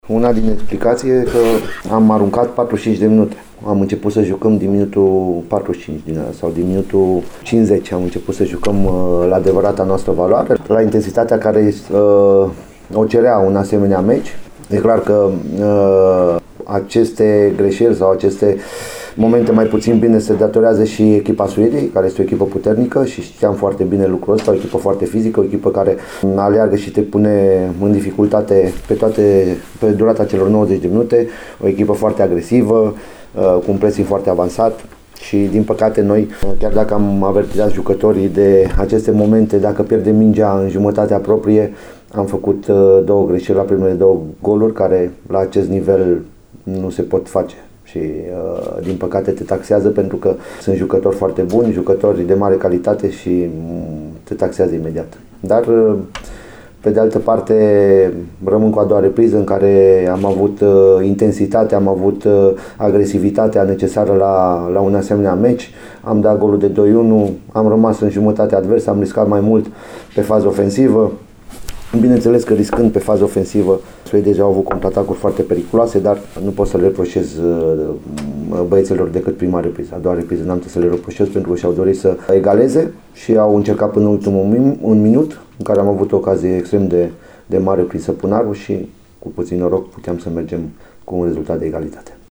Concluziile jocului le-a tras și timișoreanul Cosmin Contra, selecționerul României;